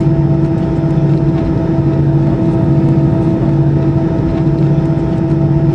IDG-A32X/Sounds/SASA/CFM56/wingaft/cfm-idle2.wav at a097f45abd1d3736a5ff9784cdaaa96ad1f1bef2
cfm-idle2.wav